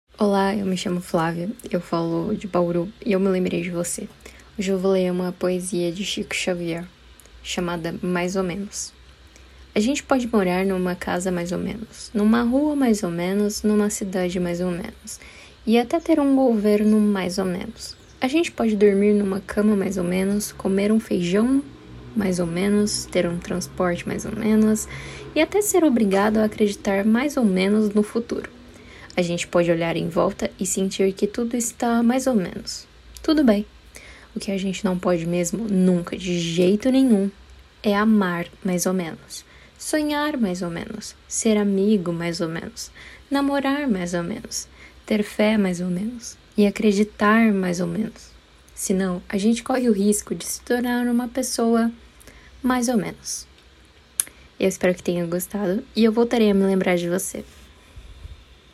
Poesia Português